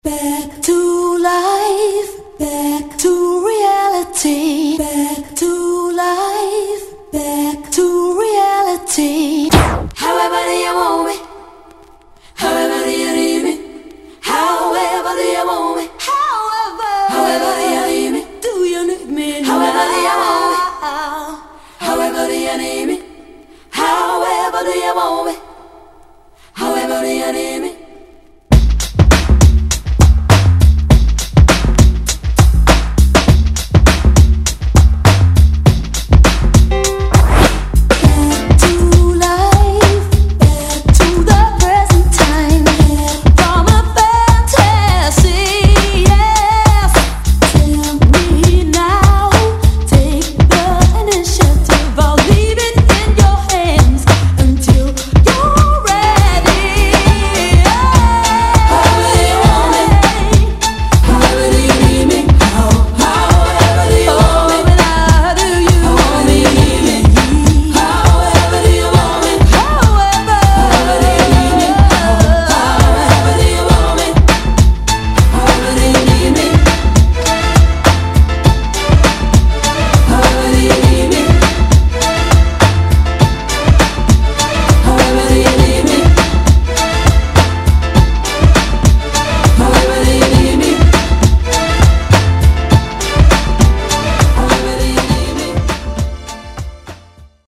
UK R&B Classic!!